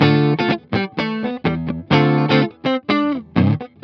15 GuitarFunky Loop D.wav